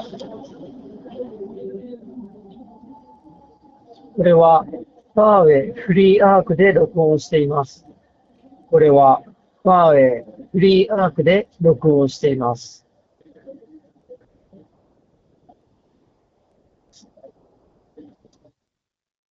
通話時のノイズキャンセリング
スピーカーから雑踏音をそこそこ大きなボリュームで流しながらマイクで収録した音声がこちら。
完全に雑音を除去しているわけではないですが、これくらいなら通話相手が聞き取りにくいことはなさそうです。
マイクも若干こもり気味なものの悪くはないので、仕事でのちょっとした打ち合わせ程度であれば十分使えると思います。